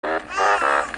Burp Burp Sound Button - Free Download & Play